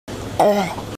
pufferfish-aeugh-sound-effect-perfect-cut.mp3